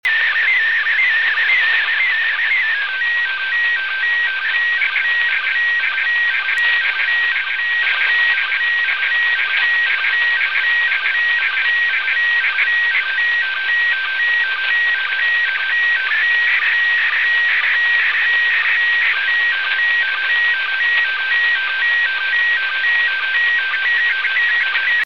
MKC SSTV